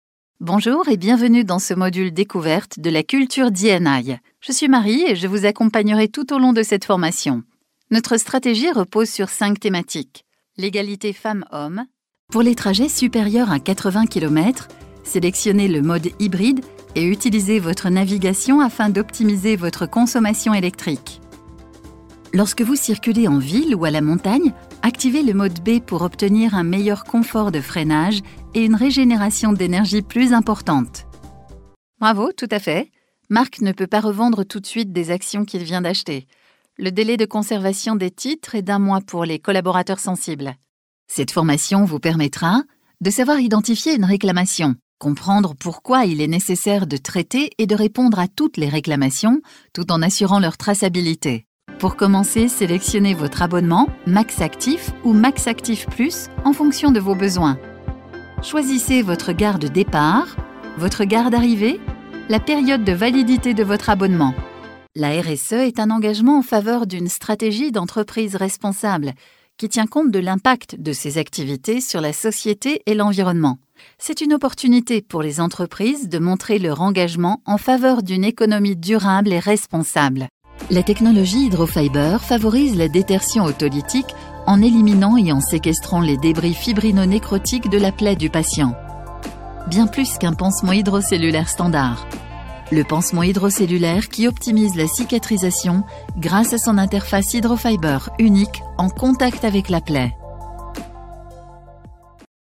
Female
Assured, Authoritative, Bright, Bubbly, Character, Cheeky, Children, Confident, Cool, Corporate, Deep, Engaging, Friendly, Gravitas, Natural, Posh, Reassuring, Sarcastic, Smooth, Soft, Streetwise, Wacky, Warm, Witty, Versatile, Young
corporate.mp3
Microphone: Neumann U87 + TLM103
Audio equipment: Apollo twin + RME fire Face ; Manley Vox Box, Booth acoustically treated